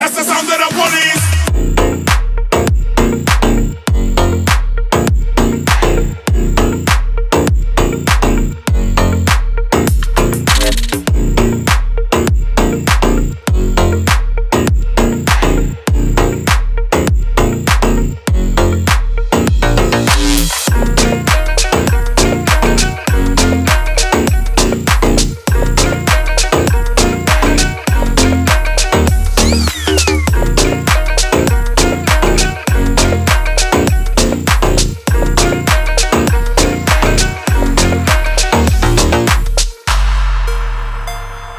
• Качество: 320, Stereo
remix
мощные басы
club
G-House
Стиль: bass house